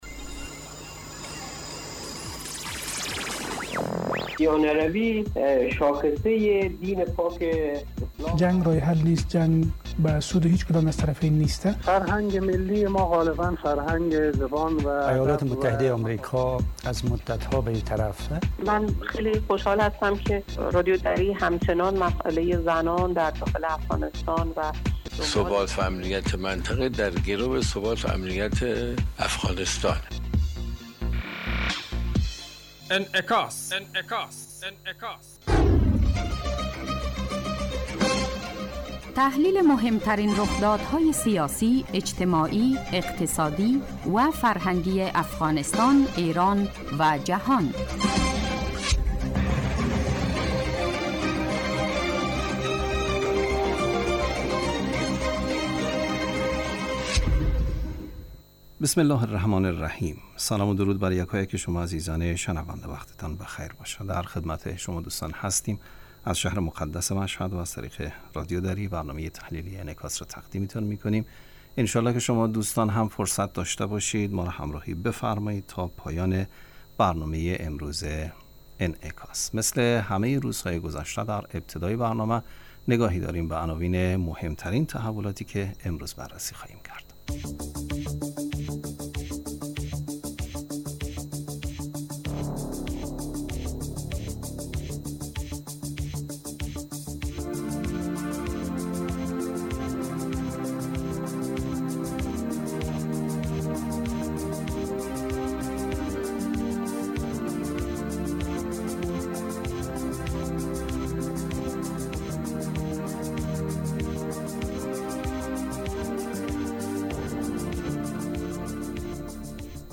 برنامه انعکاس به مدت 30 دقیقه هر روز در ساعت 12:30 ظهر (به وقت افغانستان) بصورت زنده پخش می شود.